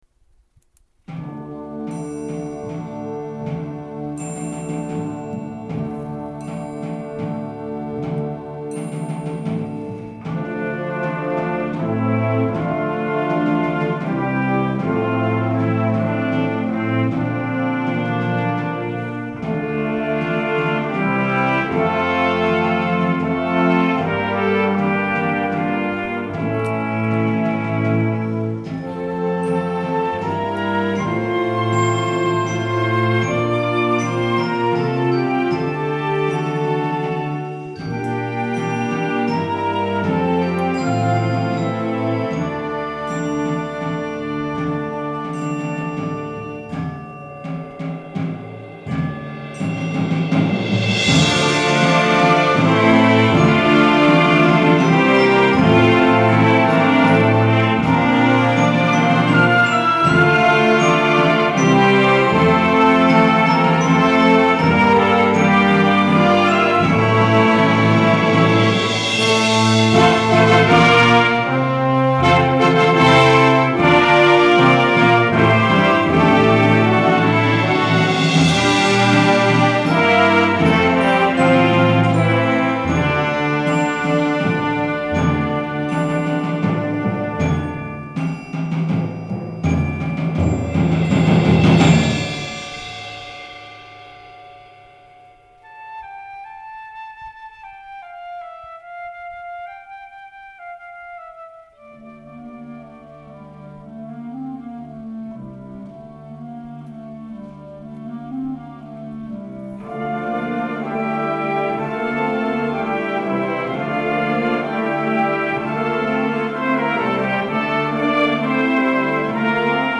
フレックス・バンド，アンサンブル曲の参考音源